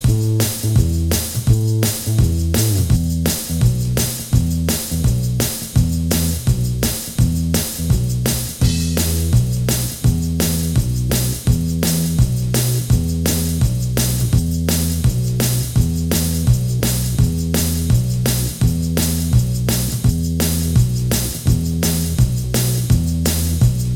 Minus Guitars Rock 3:41 Buy £1.50